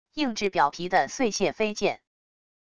硬质表皮的碎屑飞溅wav音频